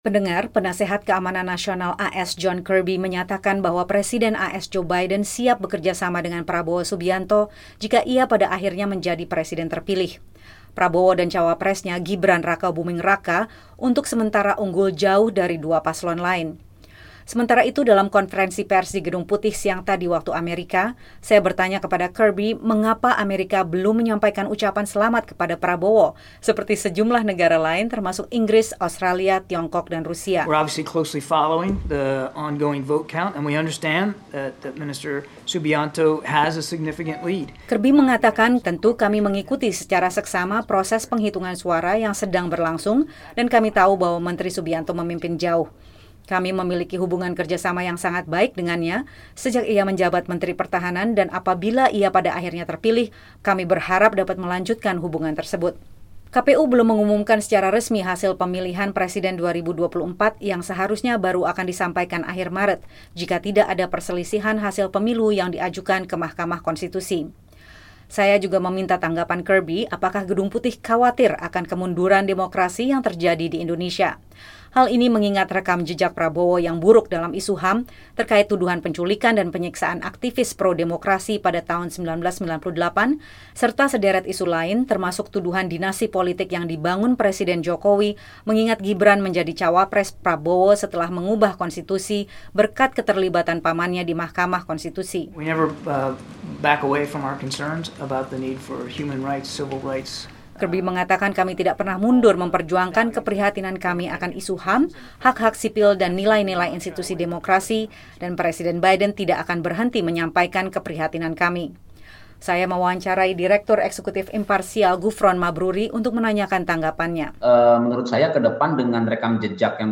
Dalam konferensi pers di Gedung Putih, Juru Bicara Dewan Keamanan Nasional AS John Kirby mengatakan Presiden AS Joe Biden siap bekerja sama dengan Prabowo Subianto jika ia pada akhirnya menjadi presiden terpilih. Prabowo-Gibran untuk sementara unggul jauh dari dua paslon lain.